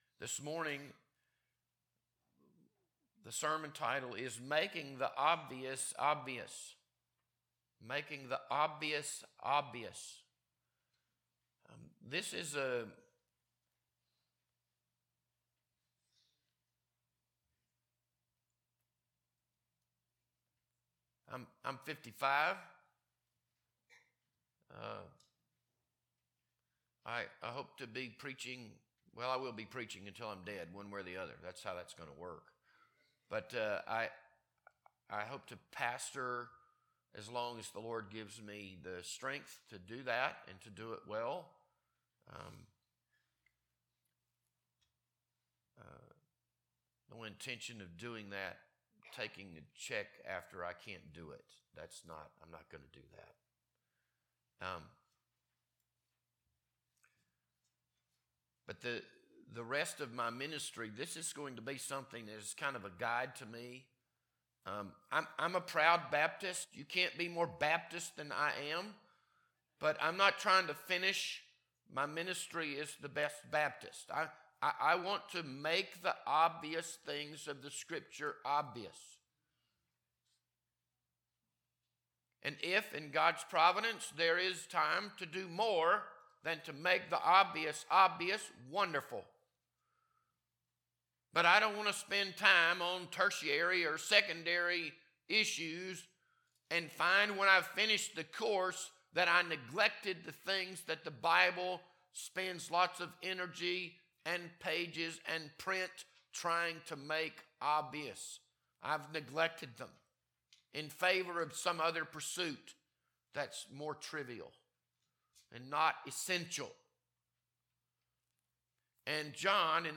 This Sunday morning sermon was recorded on March 1st, 2026.